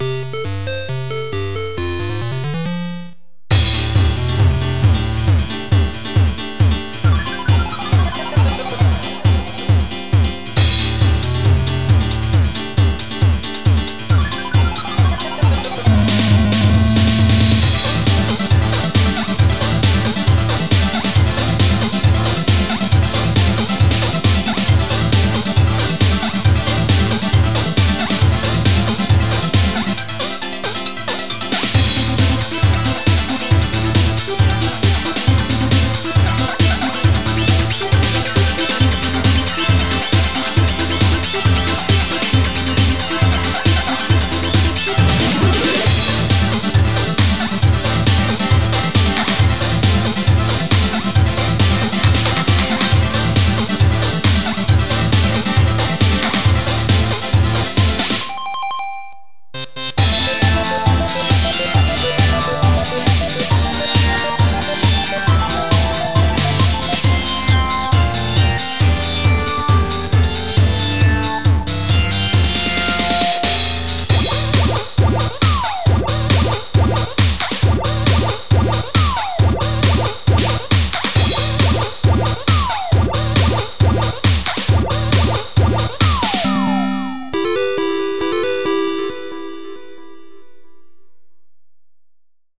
BPM136
Audio QualityPerfect (High Quality)
Better quality audio.